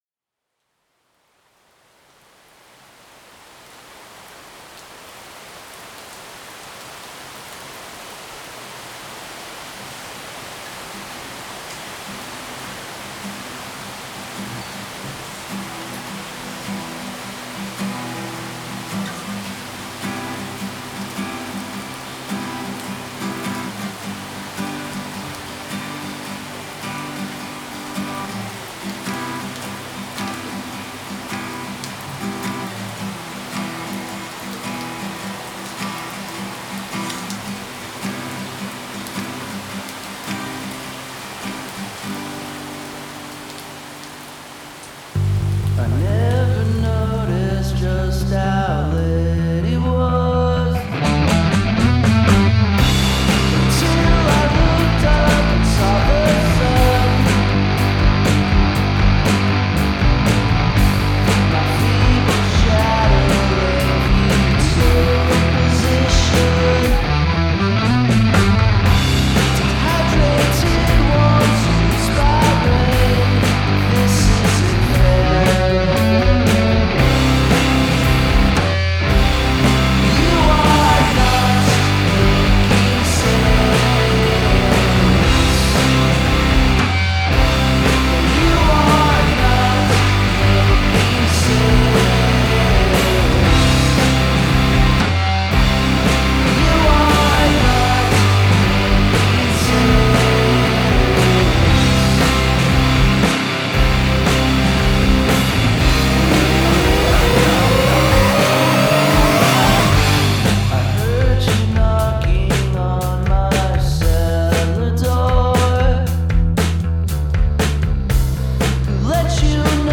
Un petit peu de rock'n'roll